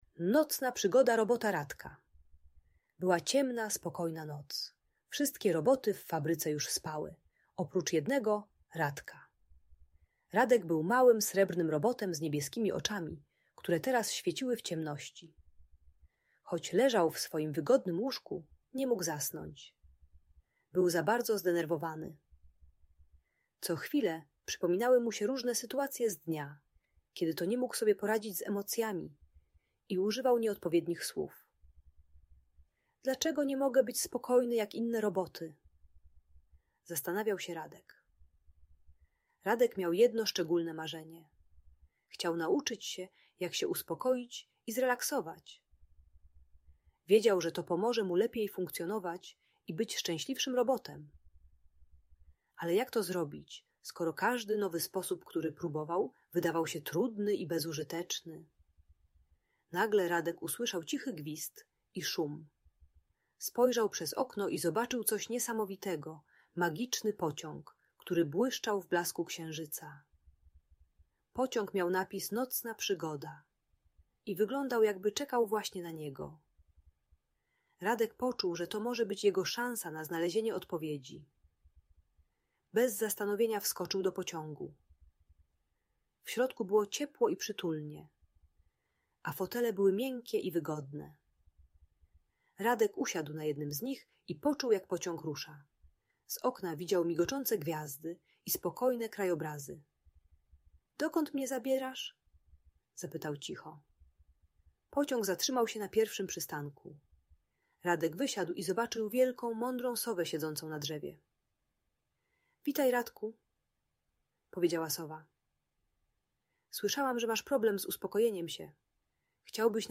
Nocna Przygoda Robota Radka - Opowieść o Emocjach - Audiobajka